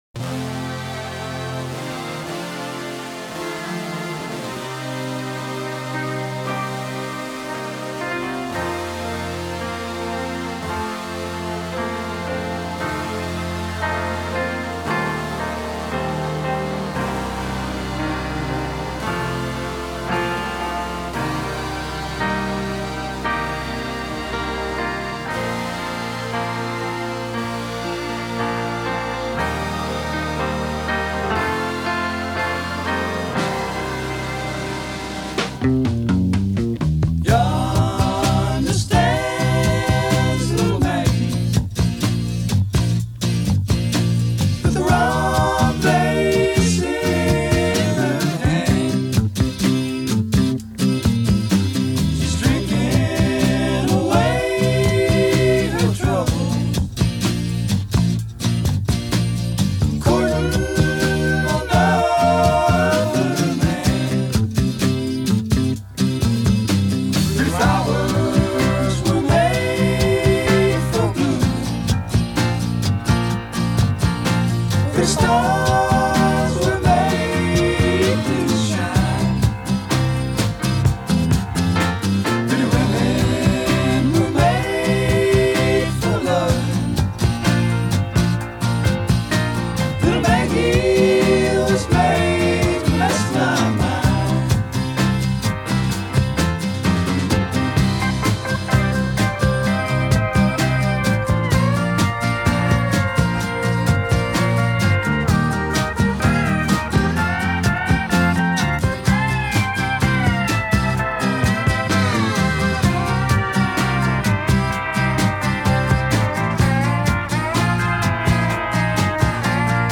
Rock sureño en EE.UU.
Es una banda estadounidense de rock sureño y música country, formada en la ciudad de Nashville, Tennessee, activa principalmente de 1971 a 1977, y con algunas reuniones ocasionales hasta la fecha.